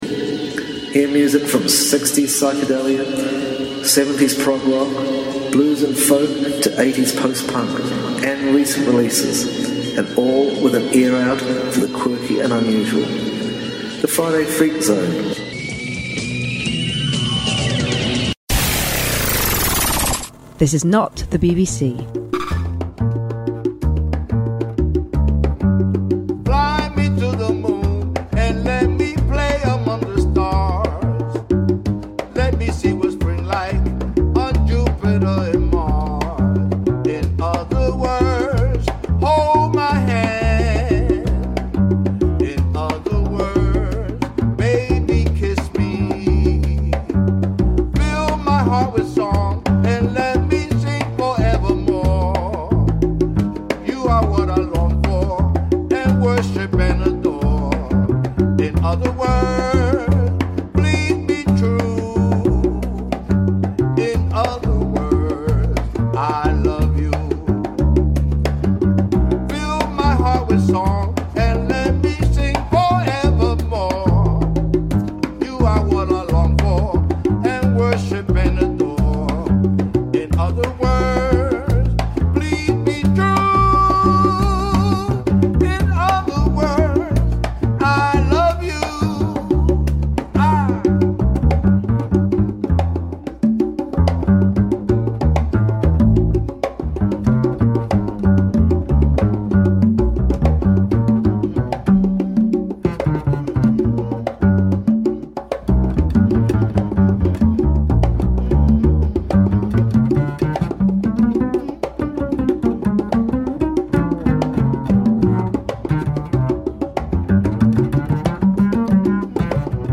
good groove for you, just like those back in the days